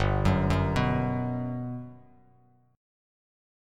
GM7 Chord
Listen to GM7 strummed